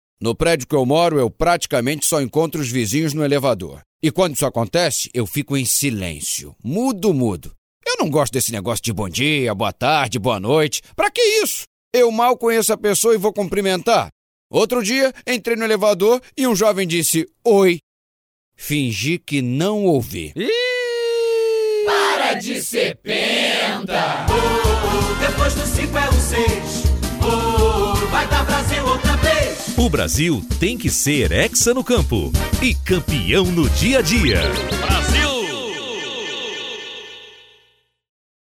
A mensagem é clara: “O Brasil tem que ser hexa no campo e campeão no dia a dia”, diz o locutor na assinatura dos spots de áudio, que vão ao ar a partir desta sexta-feira, dia 9, nas 35 emissoras da rede Rádio Globo.
A cada peça de áudio, um tipo diferente de “penta” se apresenta: um cidadão que joga lixo na rua e se irrita em saber que agora existe multa para reprimir esta sujeira; um motorista que usa celular ao volante, avança sinais e estaciona em cima de calçadas; uma pessoa que não larga o celular nem na hora de sentar com os amigos numa mesa de bar ou restaurante; e um morador de edifício que não cumprimenta os vizinhos no elevador, entre outros. Em todos os casos, ao final do texto, ouve-se o coro: “Para de ser penta!”.